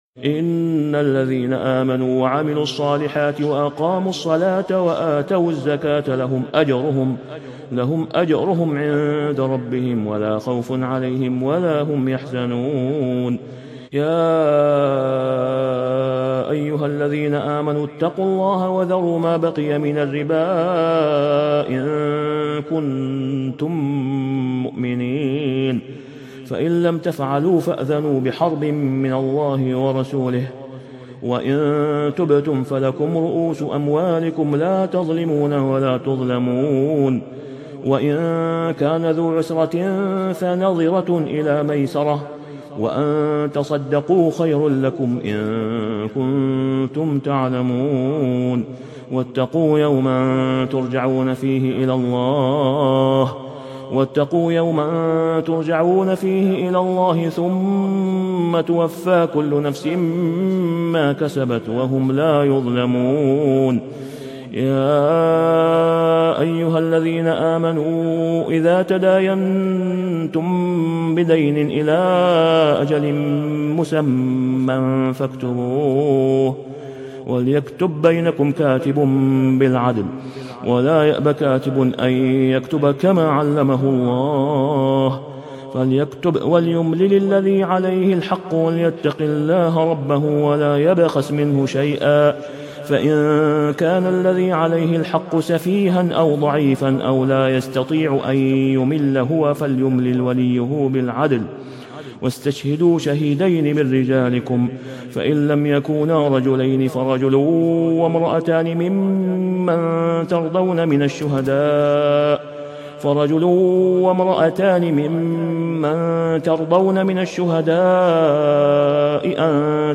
تسجيل إستديو نادر للشيخ إسامة خياط | سورة البقرة 277-286 > " تسجيلات إستديو للشيخ أسامة خياط" > المزيد - تلاوات الحرمين